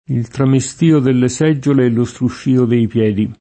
struscio [Strušš&o] s. m. («uno strusciare continuato»); pl. -scii — es. con acc. scr.: il tramestìo delle seggiole e lo struscìo dei piedi [